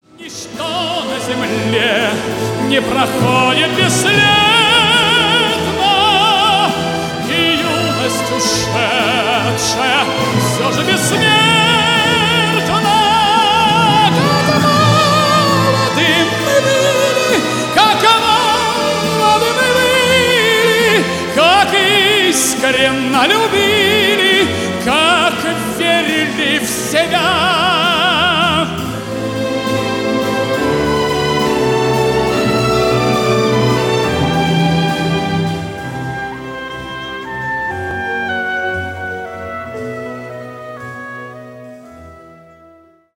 сильный голос
ретро